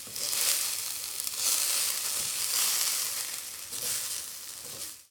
action_cook_2.ogg